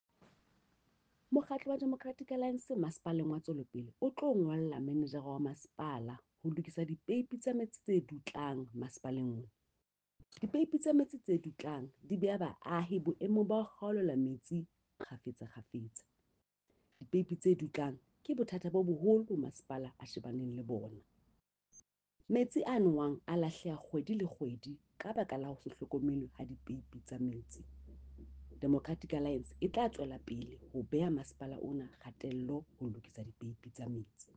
Sesotho soundbites by Cllr Mahalia Kose.